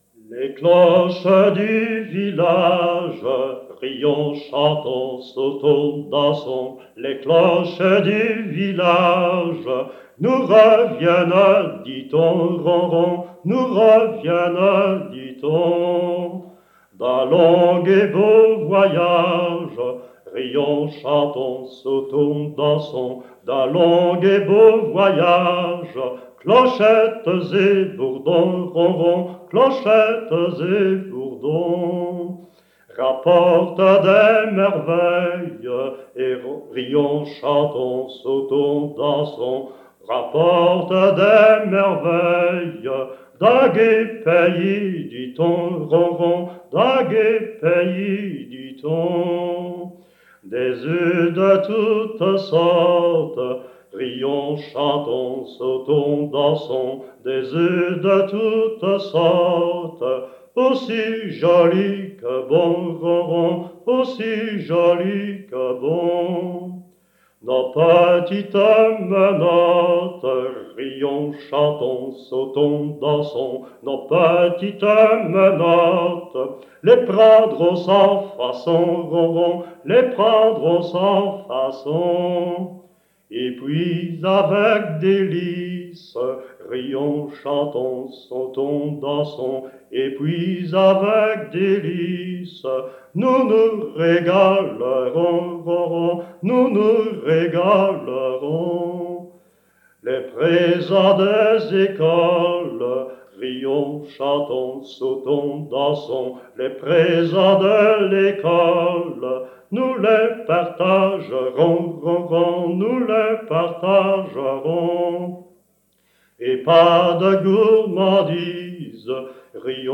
Genre : chant
Type : chanson de Pâques
Interprète(s) : Anonyme (homme)
Lieu d'enregistrement : Surice
Support : bande magnétique